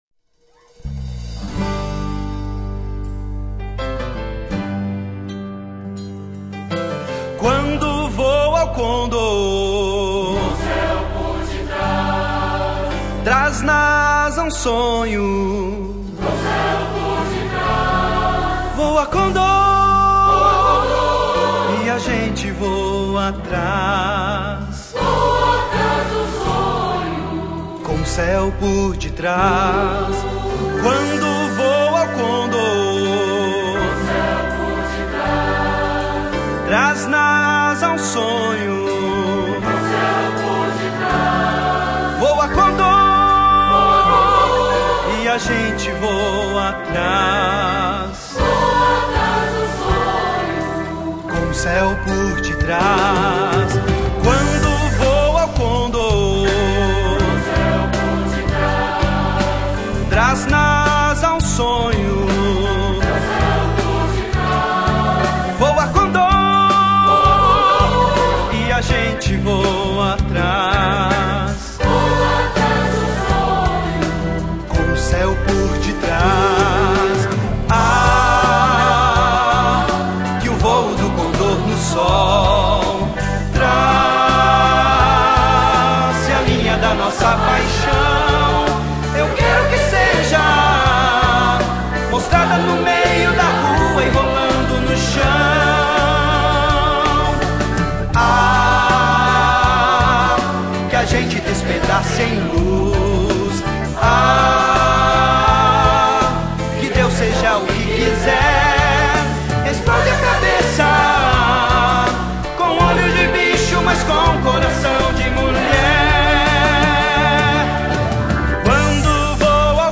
Pianistas